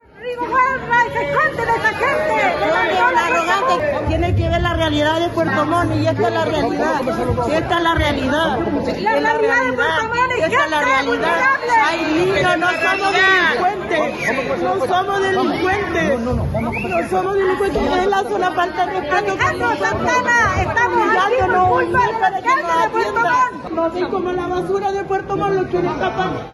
Casi al final, desde uno de los costados del auditorio y a viva voz, se escuchó la protesta de tres mujeres que representaban a los comerciantes ambulantes de Puerto Montt.